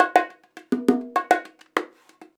100BONG10.wav